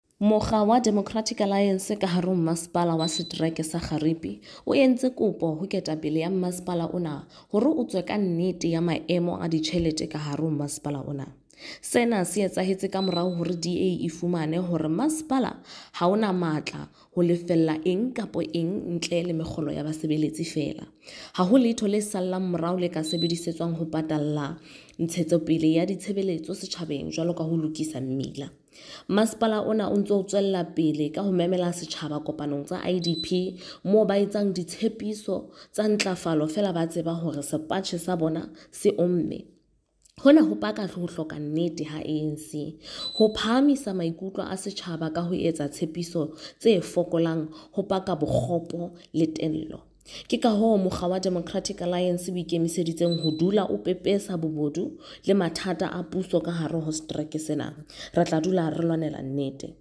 Sesotho by Karabo Khakhau MP.
Sotho-voice-Karabo-5.mp3